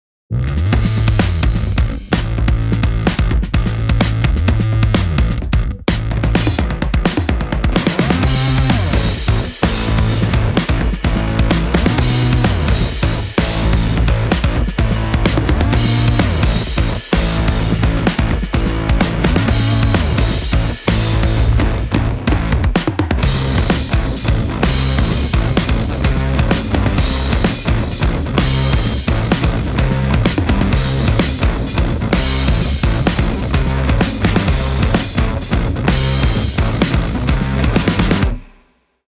どんなトラックやミックスも、よりハードにヒットするウルトラデッド・ヴィンテージ・ドラムが魂を吹き込む